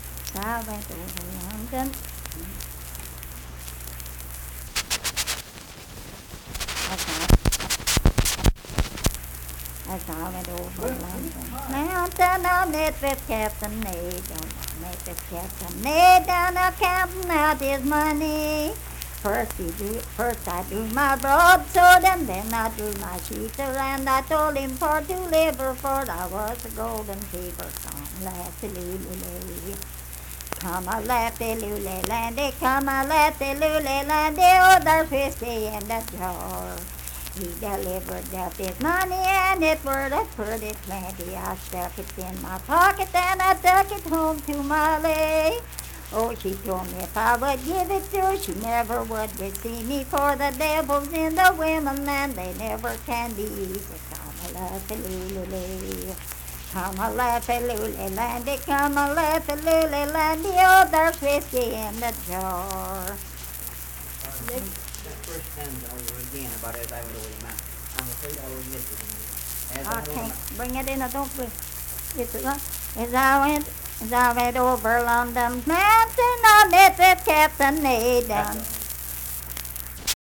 Unaccompanied vocal music performance
Verse-refrain 2(8) & R(4).
Voice (sung)